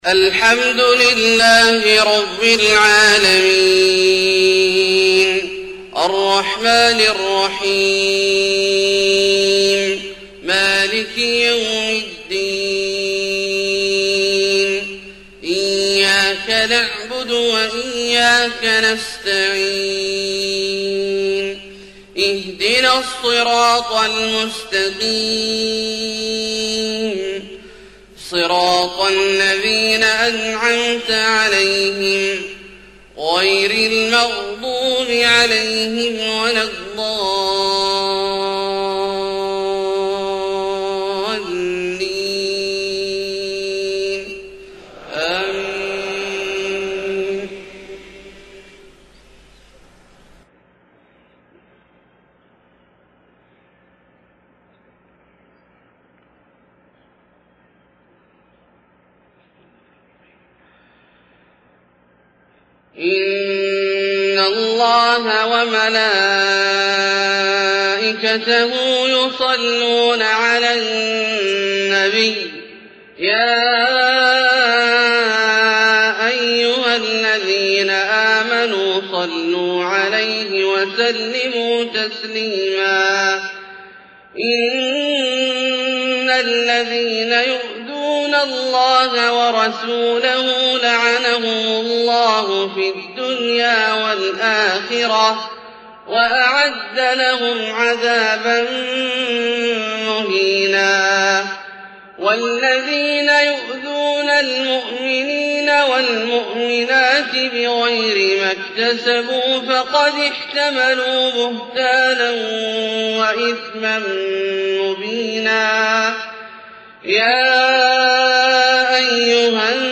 فجر 5-7-1430 من سورة الأحزاب {56-73} > ١٤٣٠ هـ > الفروض - تلاوات عبدالله الجهني